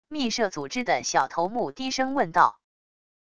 秘社组织的小头目低声问道wav音频